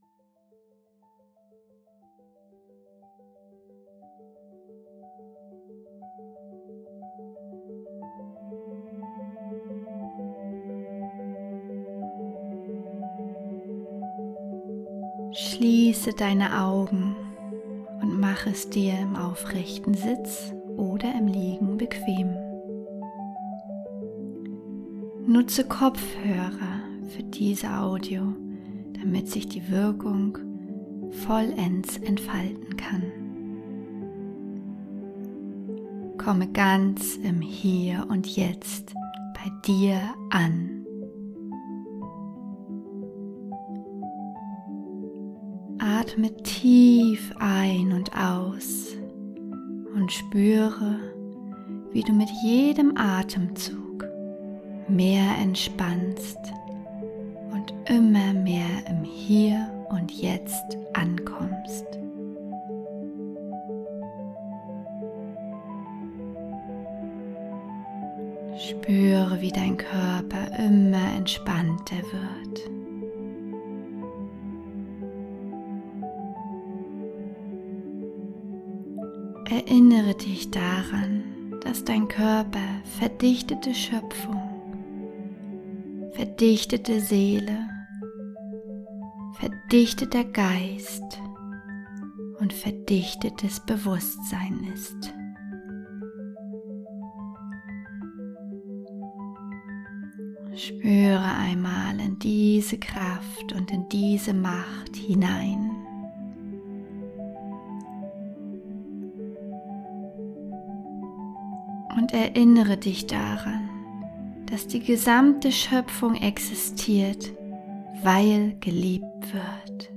Meditation: Frei von Konditionierungen und Manipulationen
Wichtige Hinweise: Nutze unbedingt kabelgebundene Kopfhörer , damit die binauralen Beats ihre volle Wirkung entfalten können.
Der summende Ton im Hintergrund ist der Binaurale Ton, der dein Gehirn in den Theta-Zustand bringt Die Audio beinhaltet multiple Informationsströme, die dein Unterbewusstsein aufnehmen kann